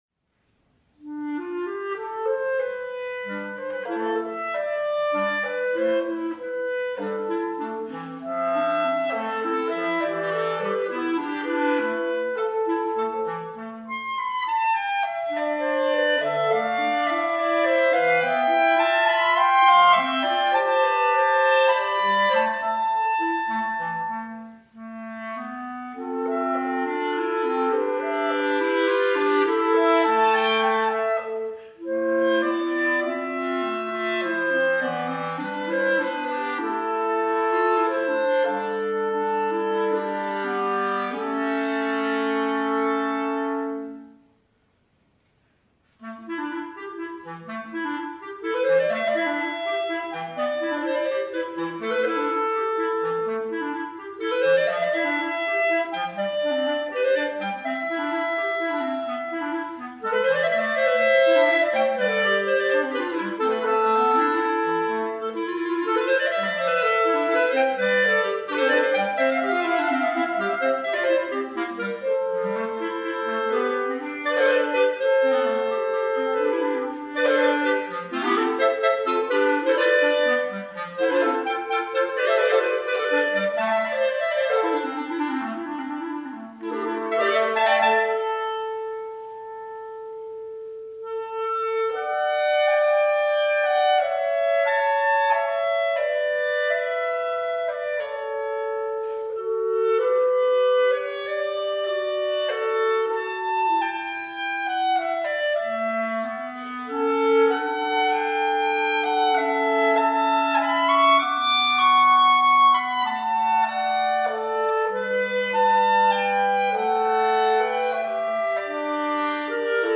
编制：Cl / Cl / Cl
B♭ Clarinet 1
B♭ Clarinet 2
B♭ Clarinet 3
忧郁中略带哀伤的曲调，与落叶随风飘舞的画面，以及清澈天空中开拓光明未来的旋律交织构成。